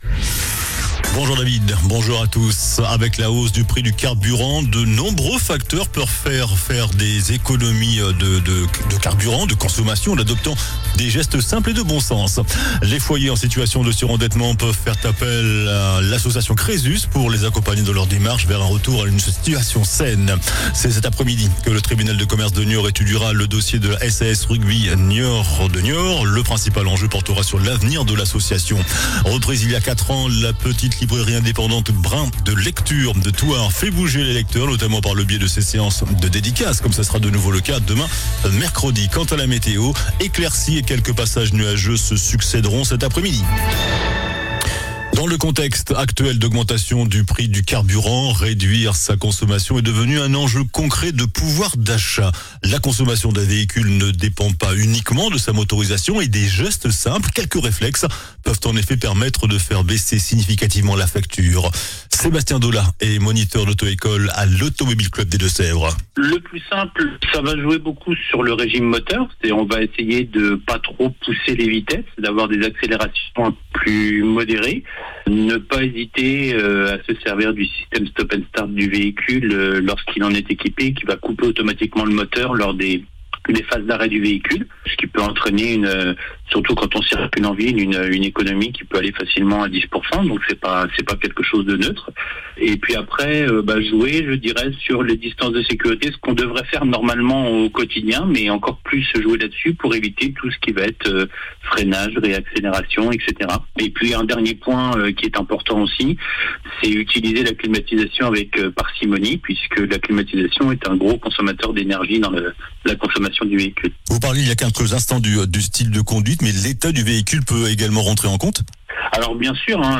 JOURNAL DU MARDI 10 MARS ( MIDI )